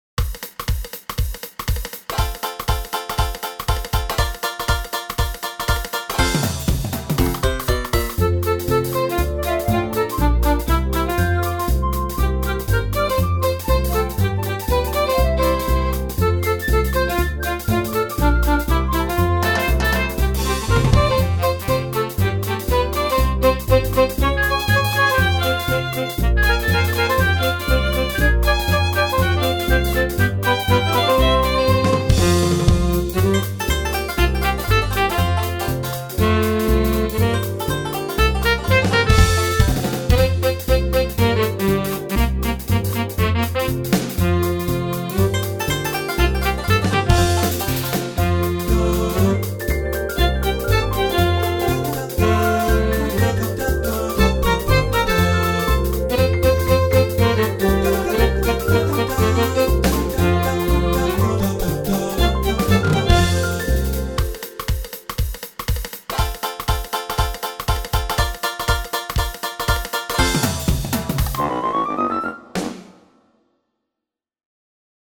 MUSIQUE  (mp3)